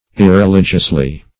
\Ir`re*li"gious*ly\